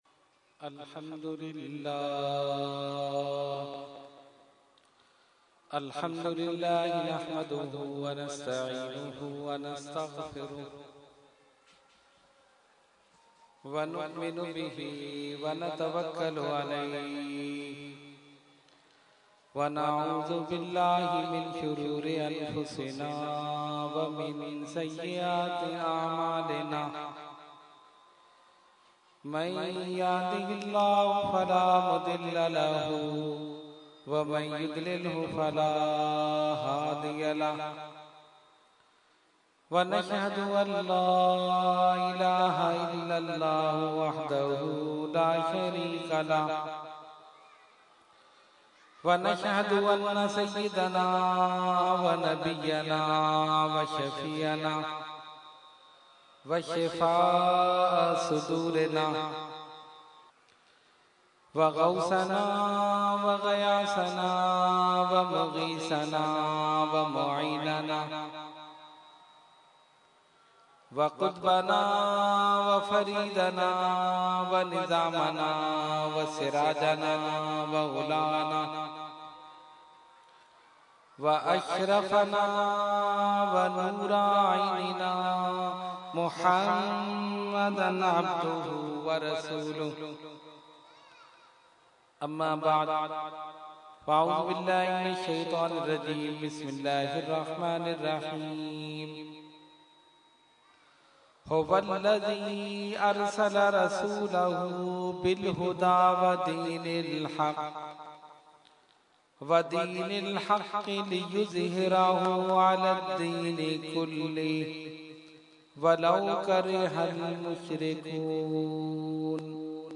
Category : Speech | Language : UrduEvent : Muharram ul Haram 2014